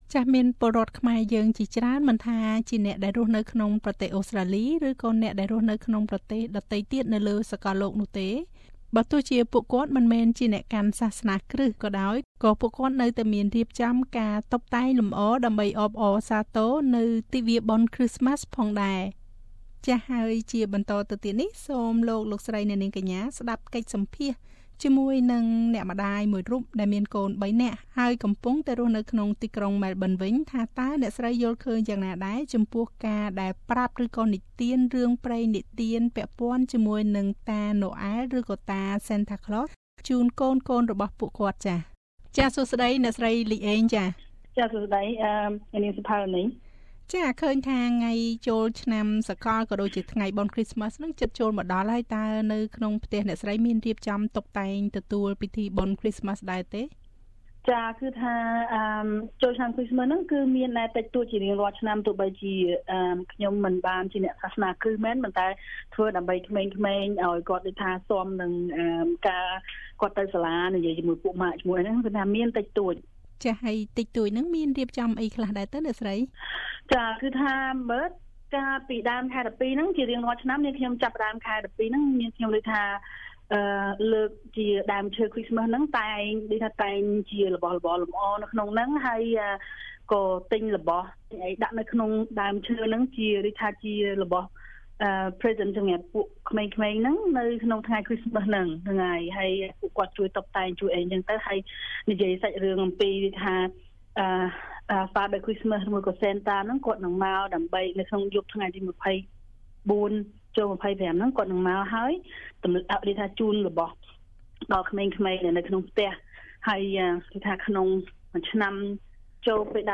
សូមស្តាប់កិច្ចសម្ភាសន៍ជាមួយនឹងអ្នកម្តាយមួយរូបដែលមានកូនបីនាក់រស់នៅក្នុងក្រុងម៉ែលប៊ិន ថាតើអ្នកស្រីយល់ឃើញយ៉ាងណាដែរចំពោះការនិទានរឿងព្រេងពាក់ព័ន្ធនឹងទិវាបុណ្យChristmasនេះជូនដល់កូនៗរបស់គាត់។